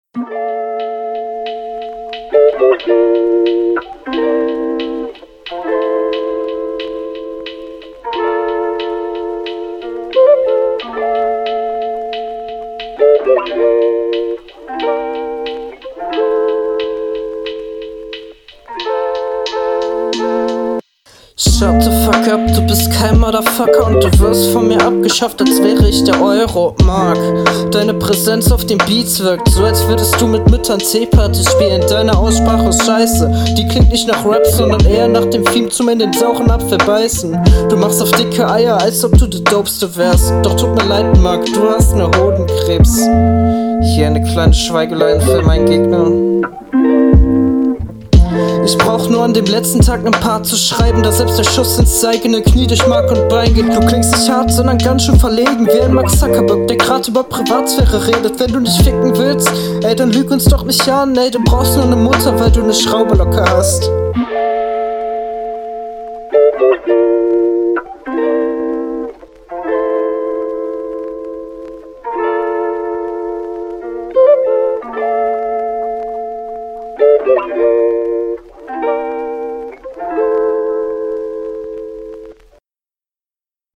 Chillig.